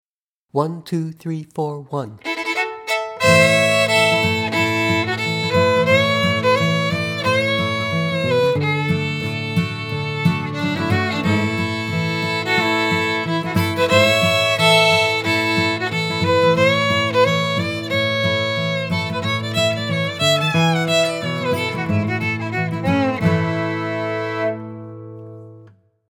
easier version, my arrangement, A